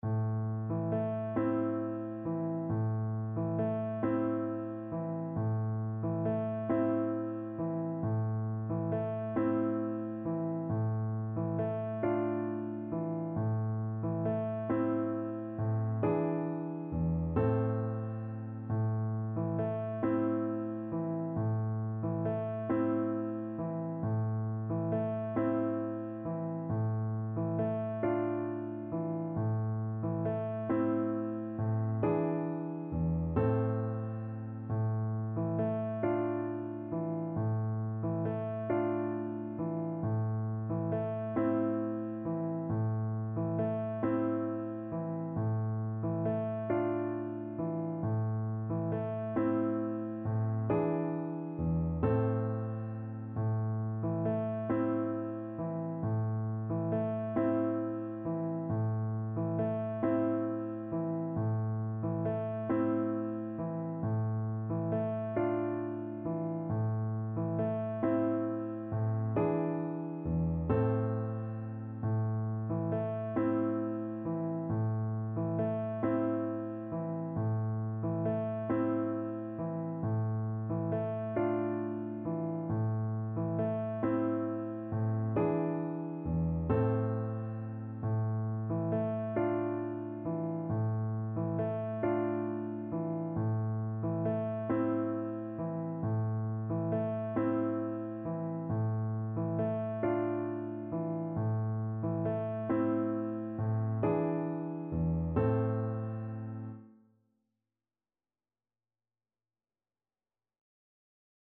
Gently rocking .=c.45
A minor (Sounding Pitch) (View more A minor Music for Violin )
Classical (View more Classical Violin Music)
Turkish
sari_gelin_VLN_kar1.mp3